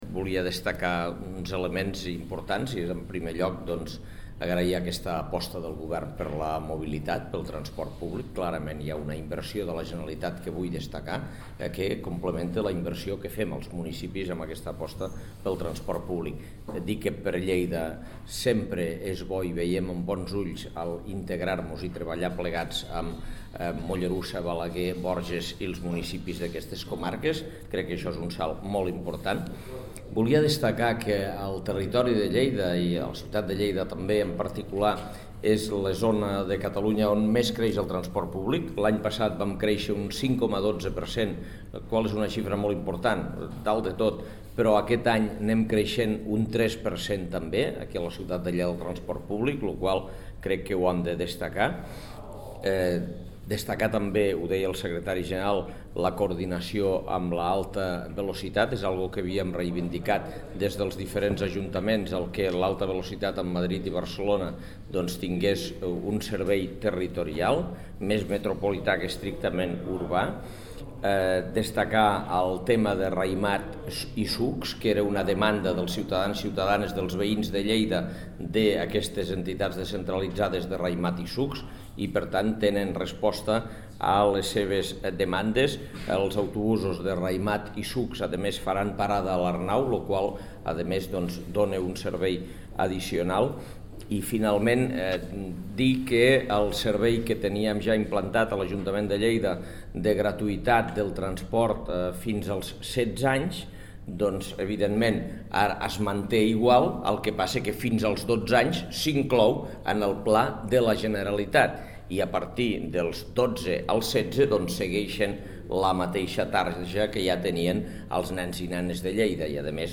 tall-de-veu-de-lalcalde-angel-ros